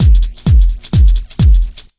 129BEAT1 5-L.wav